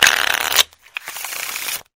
pokercard.wav